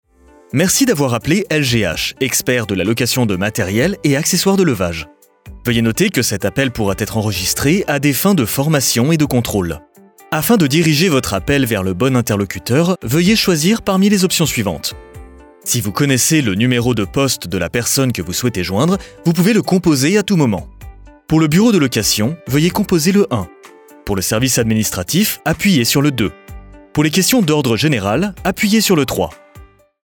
Natural, Versatile, Reliable, Friendly, Corporate
Telephony
His natural mid-low voice sounds professional and trustworthy but also warm and friendly - perfect for corporate and educational content, while his versatility allows him to voice a wide range of characters and commercials.